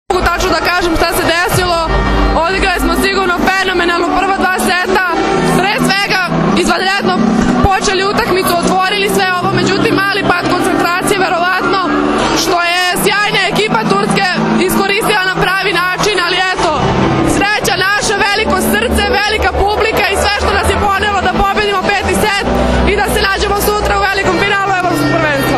IZJAVA NATAŠE KRSMANOVIĆ